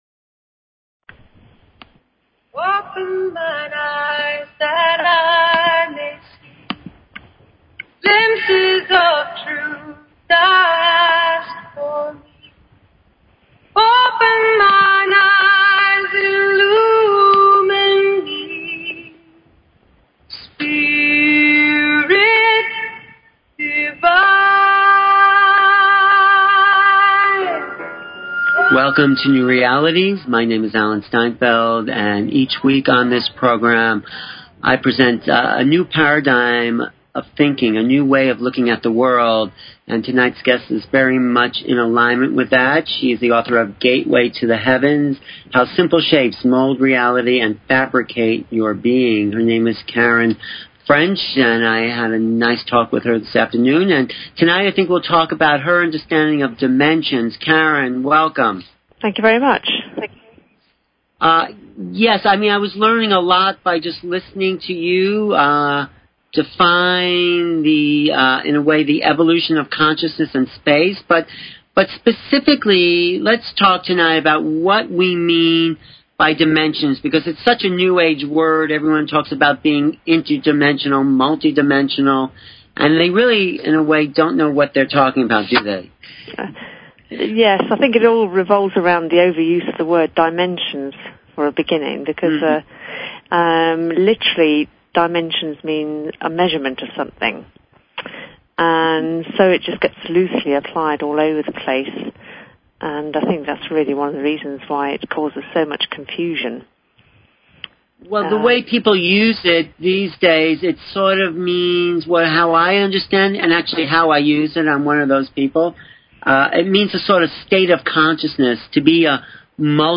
New Realities Talk Show